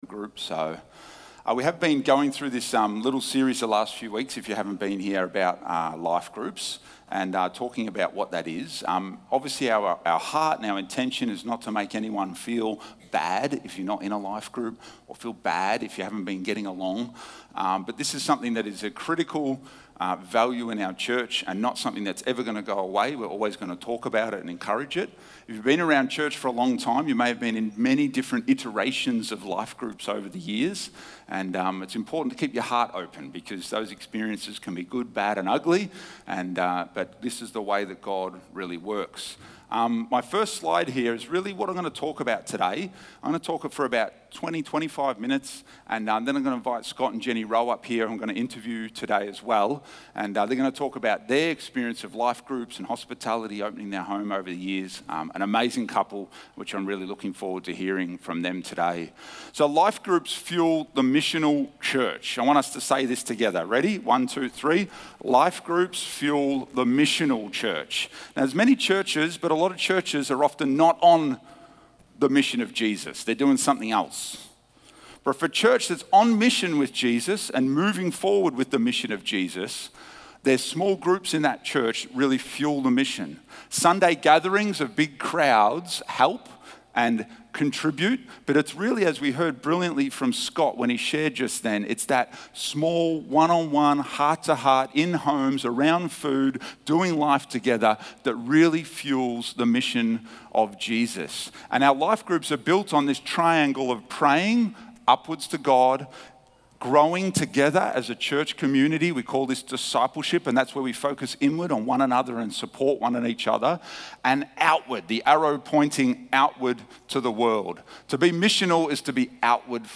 Sermons | Discover Church